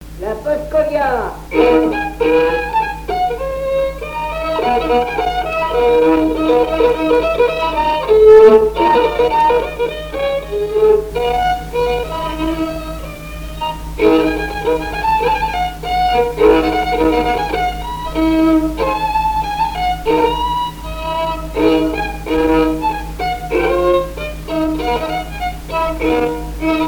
danse : paskovia
Pièce musicale inédite